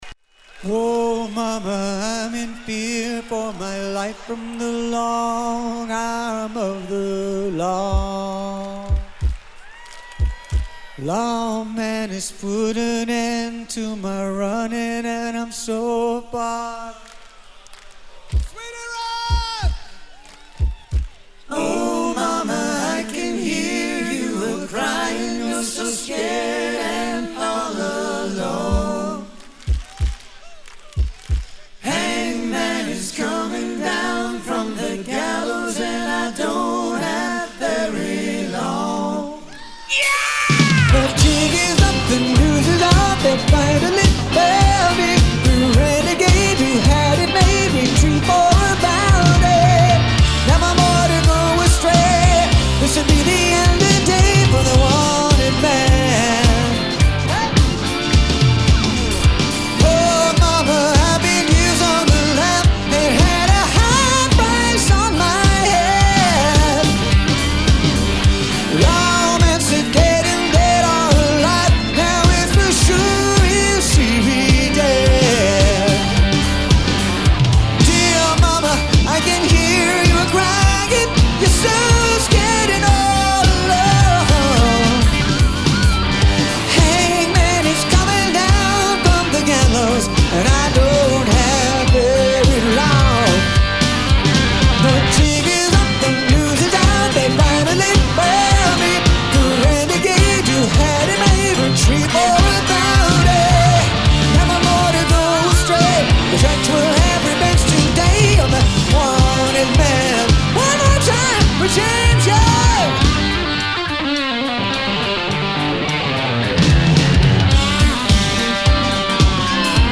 outdoor festival
spirited performance